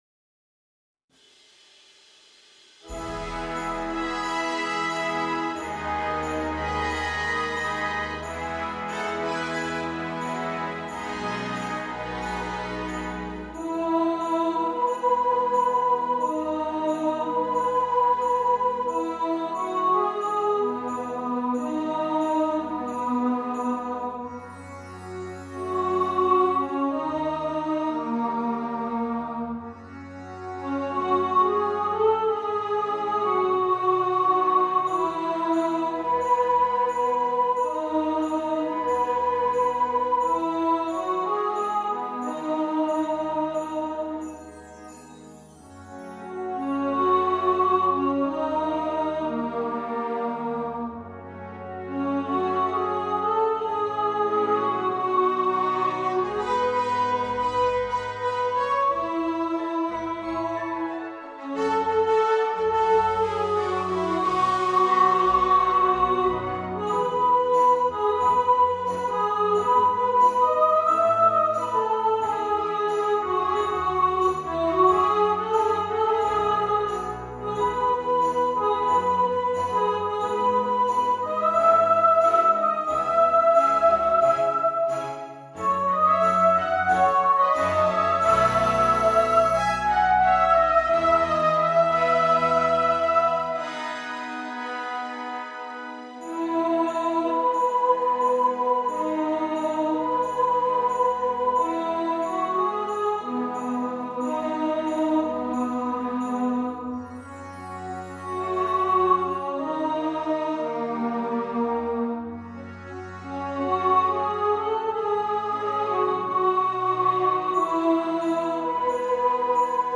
Believe (Polar Express) – Soprano | Ipswich Hospital Community Choir